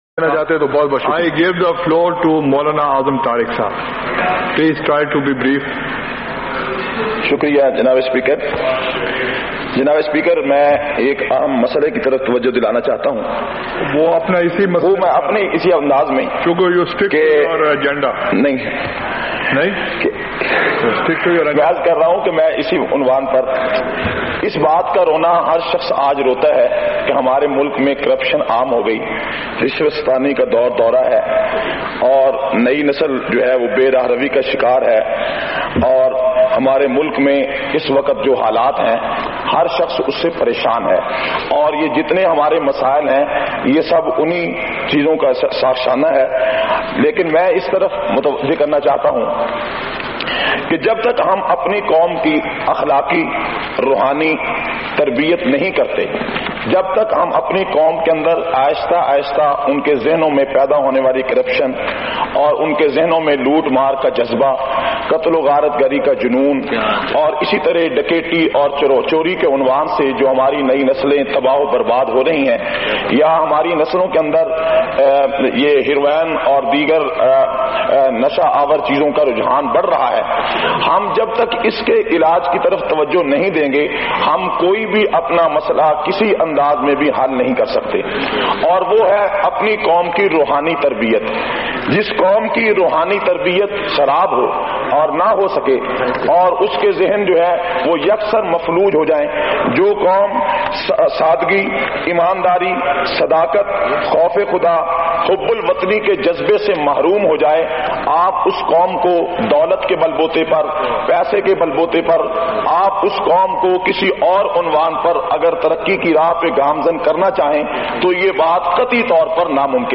450- Qaomi Assembly Khutbat Vol 4.mp3